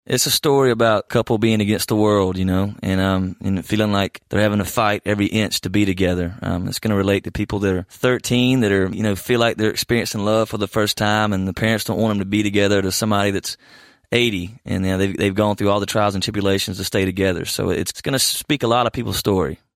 AUDIO: Kip Moore talks about his new song, “Young Love.”